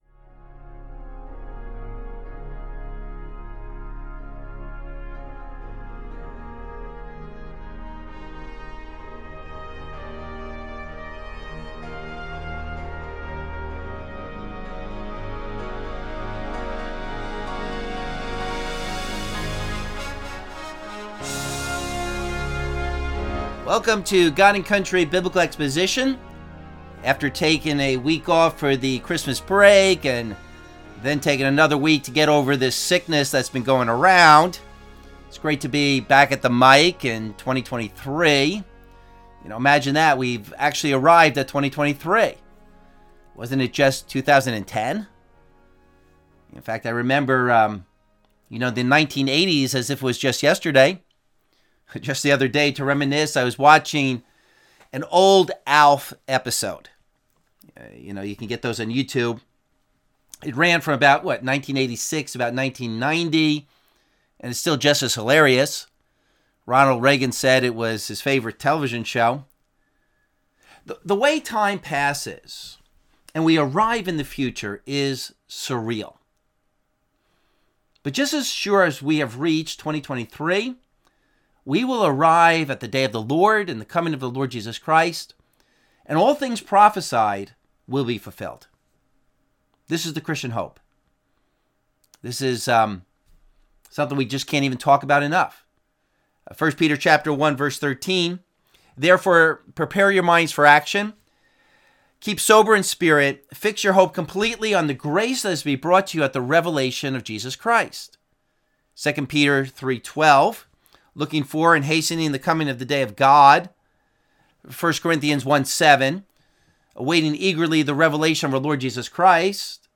Abridged Radio Program